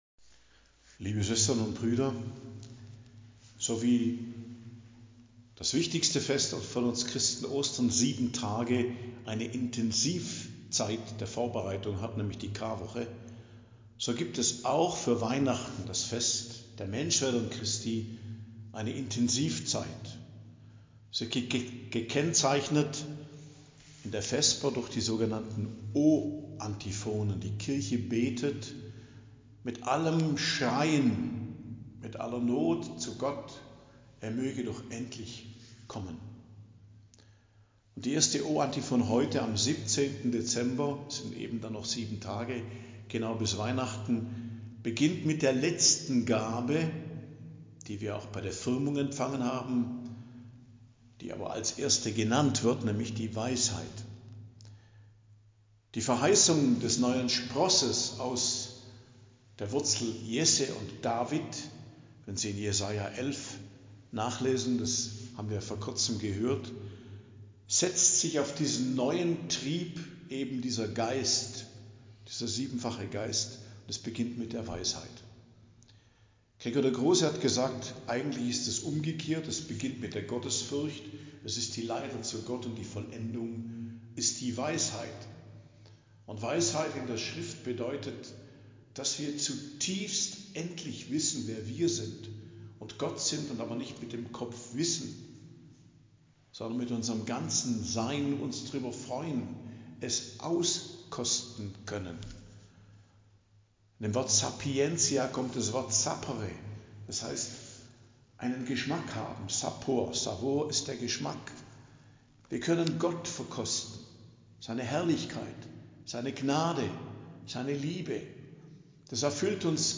Predigt am Dienstag der 3.